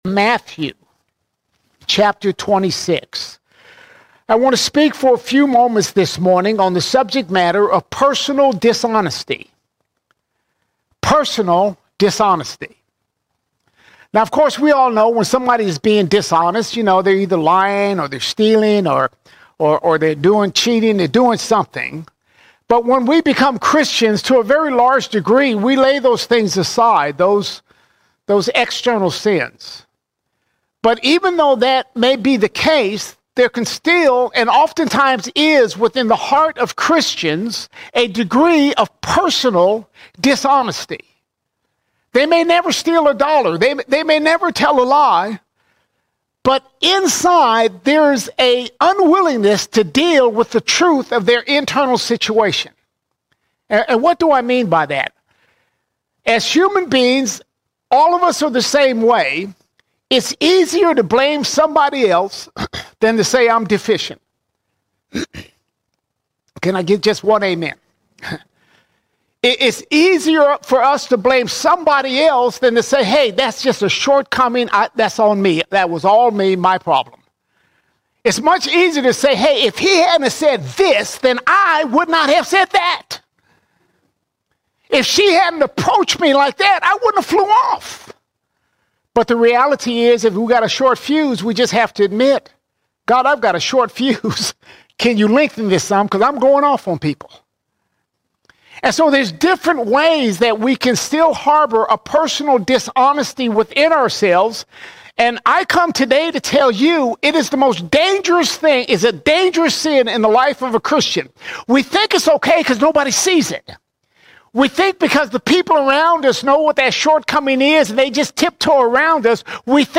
17 March 2025 Series: Sunday Sermons All Sermons Personal Dishonesty Personal Dishonesty Walking with Jesus means moving beyond surface honesty, embracing true honesty before God and ourselves.